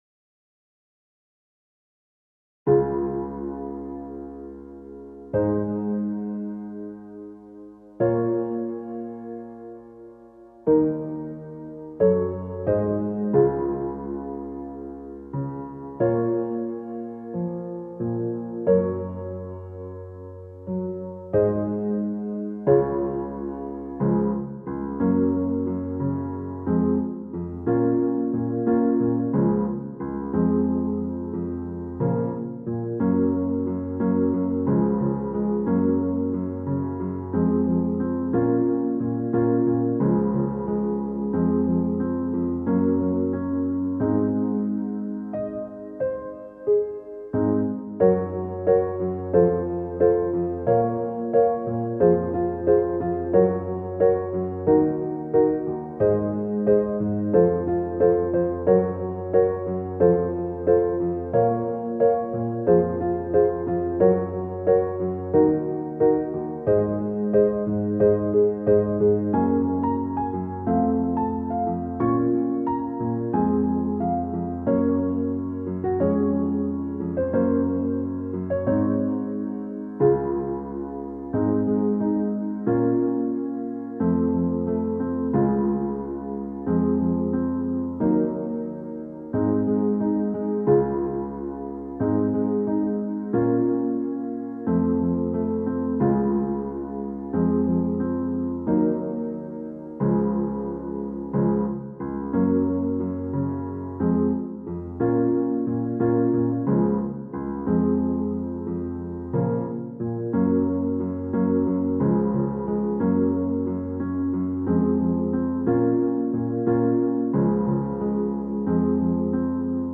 インスト音源 / コード譜 配布